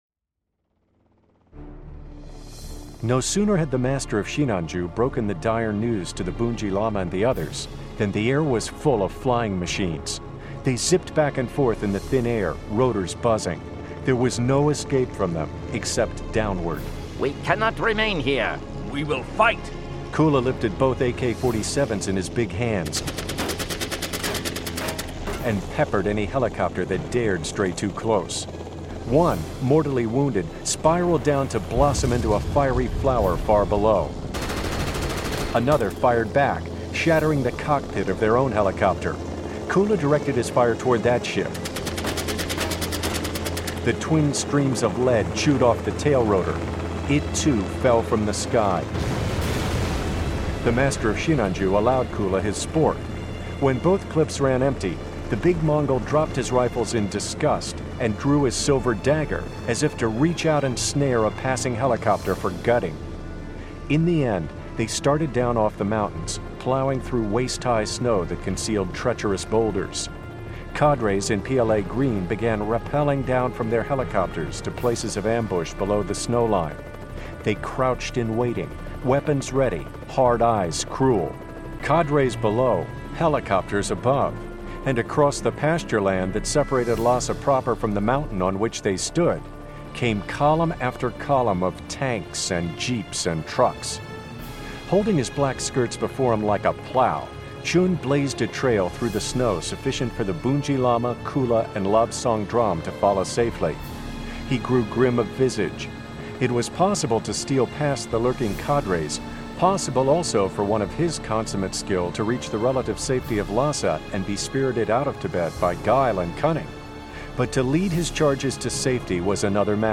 Full Cast. Cinematic Music. Sound Effects.
Genre: Action Adventure